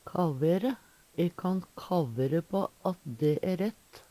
DIALEKTORD PÅ NORMERT NORSK kavere vere heilt sikker Infinitiv Presens Preteritum Perfektum kavere kaverar kavera kavera Eksempel på bruk E kan kavere på at dæ æ rett.